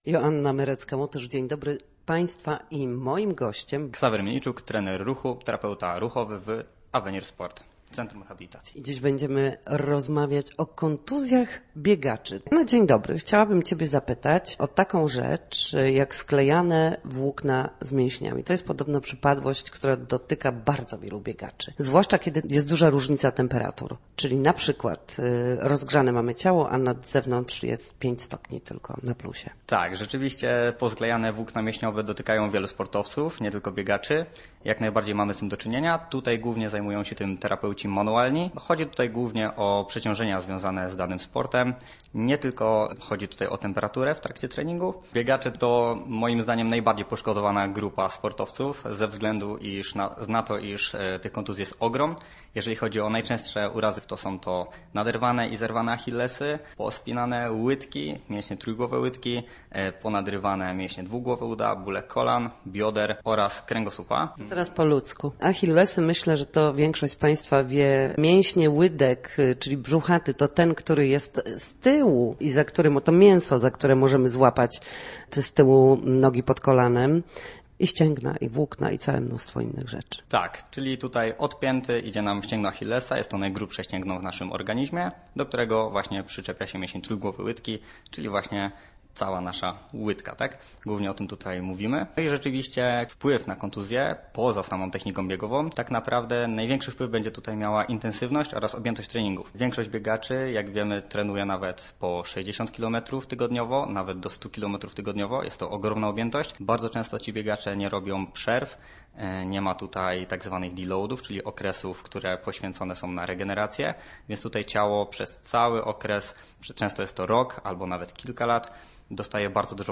W każdą środę, w popołudniowym Studiu Słupsk Radia Gdańsk, rozmawiamy o tym, jak wrócić do formy po chorobach i urazach.